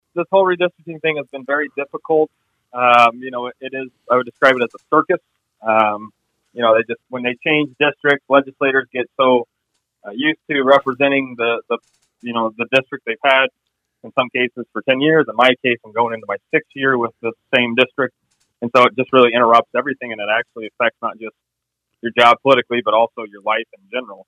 Wheeler tells KIWA that redistricting has been difficult for legislative incumbents.